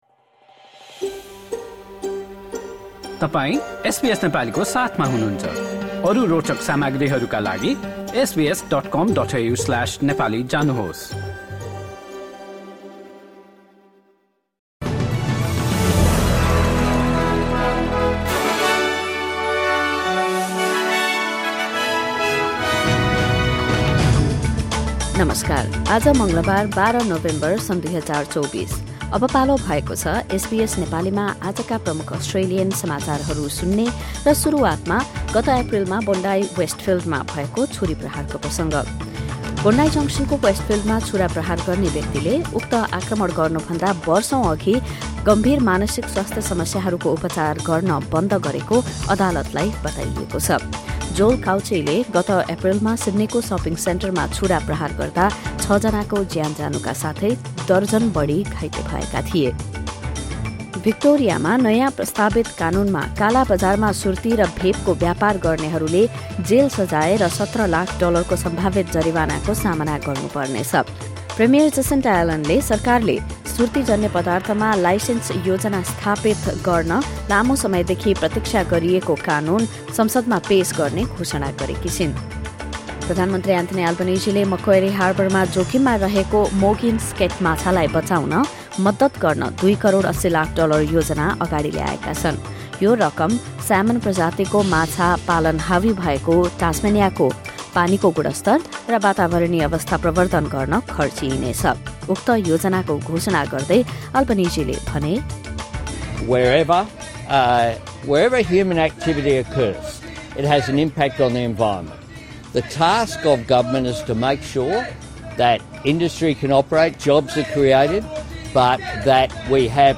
Listen to the latest top news from Australia in Nepali.